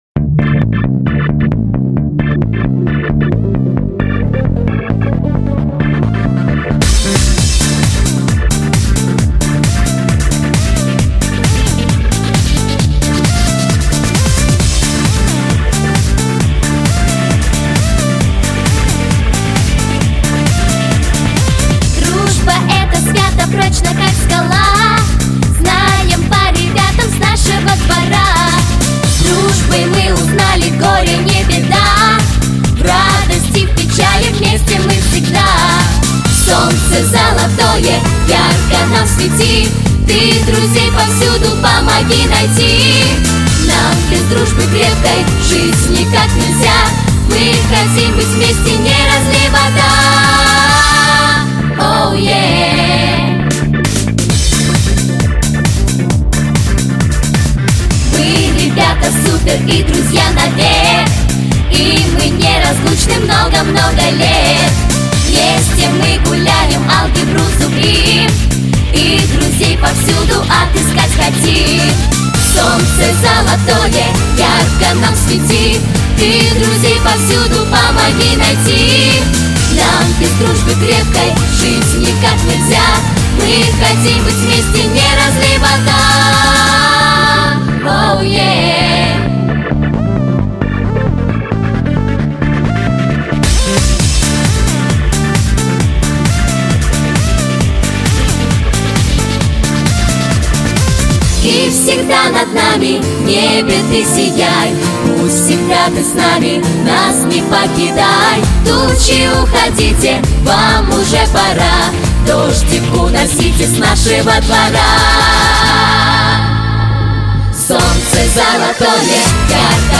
Песни про друзей